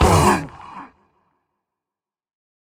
sounds / mob / warden / hurt_3.ogg
hurt_3.ogg